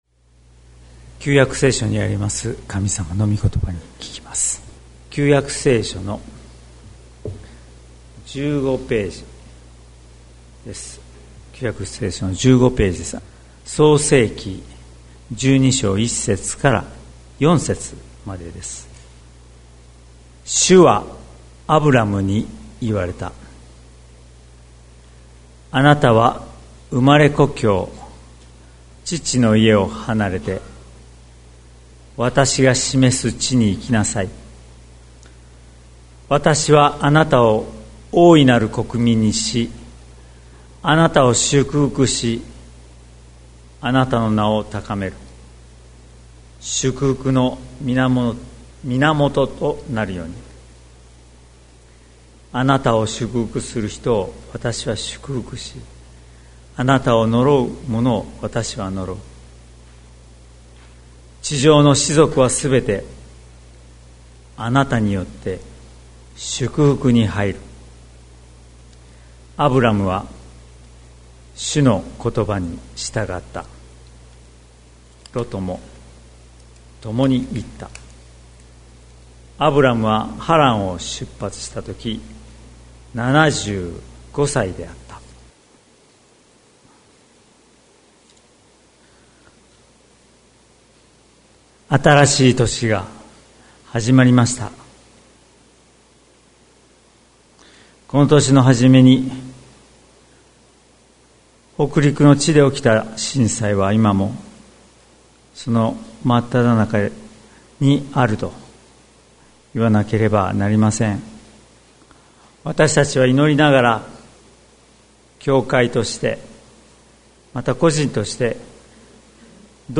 2024年01月14日朝の礼拝「旅のはじまり」関キリスト教会
説教アーカイブ。